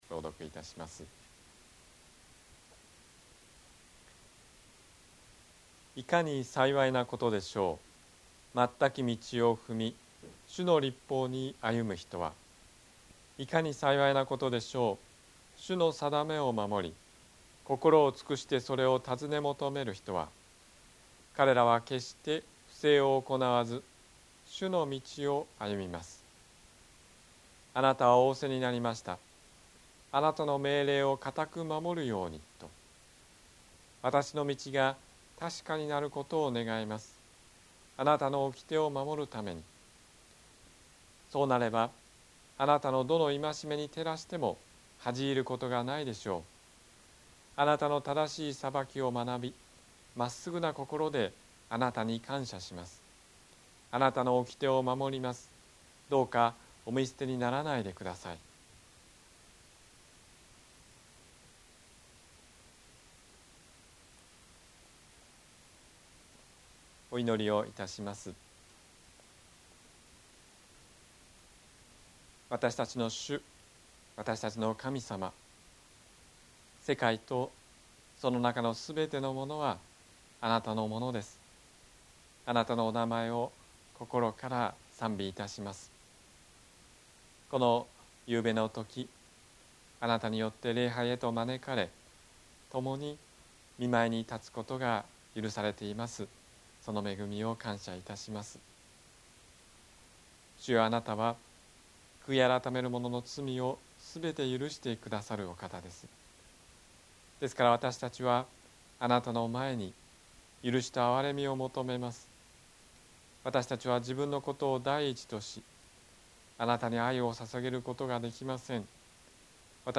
日曜 夕方の礼拝
説教